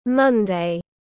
Thứ 2 tiếng anh gọi là monday, phiên âm tiếng anh đọc là /ˈmʌn.deɪ/
Monday /ˈmʌn.deɪ/